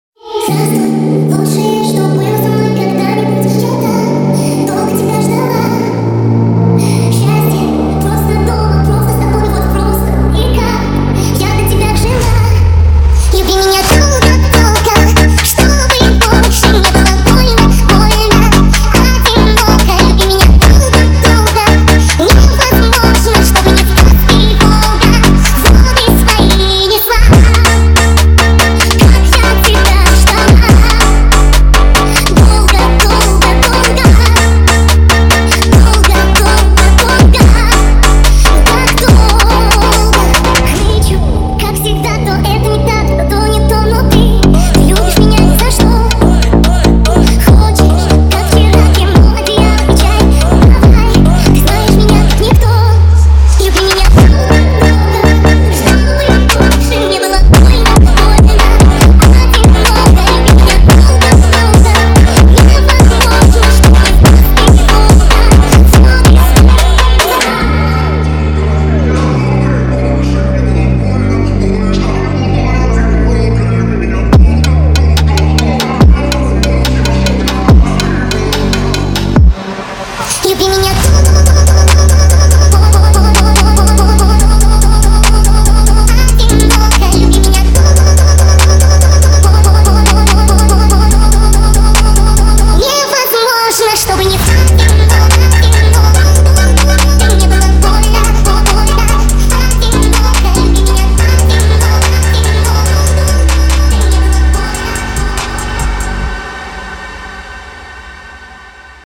Скачать музыку / Музон / Speed Up